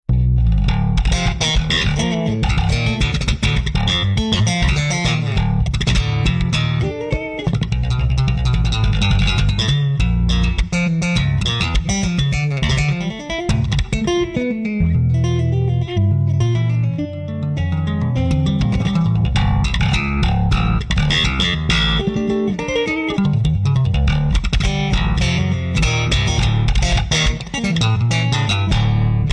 🔥 with Ample Bass TR6 that can do it all!